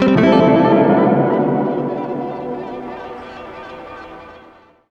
GUITARFX14-R.wav